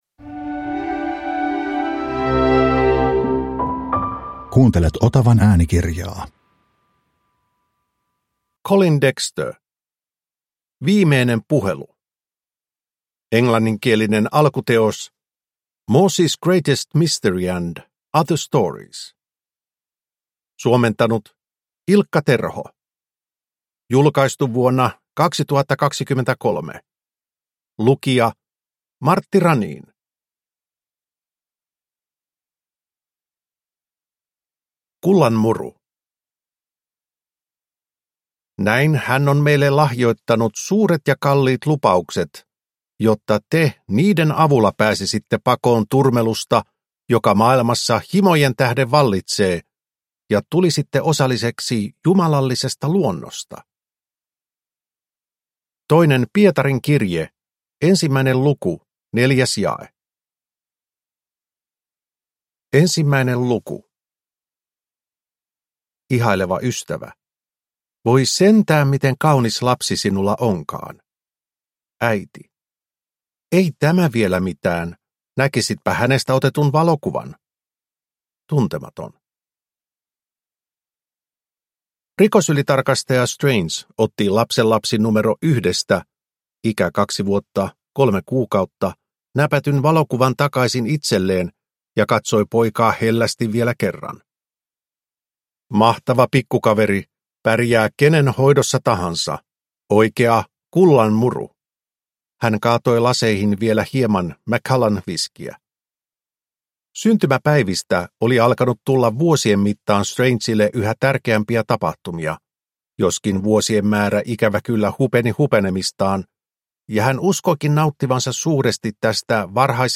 Viimeinen puhelu – Ljudbok